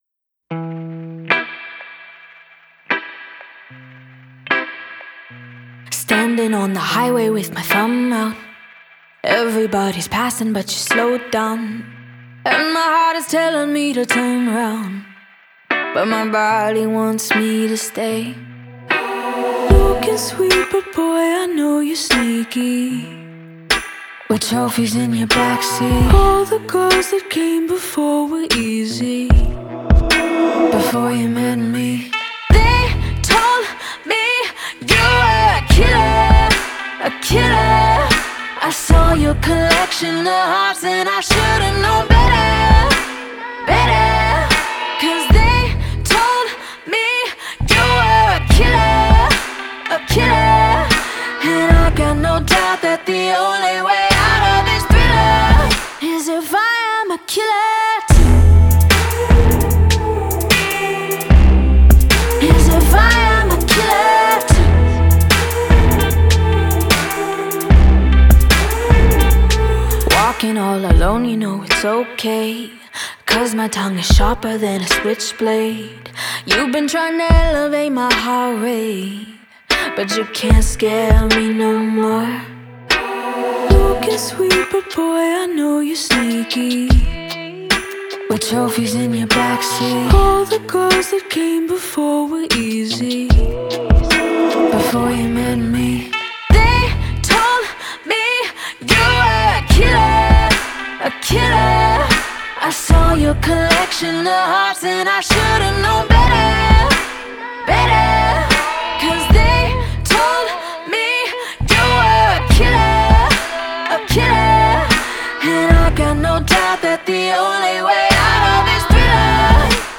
Спокойненько так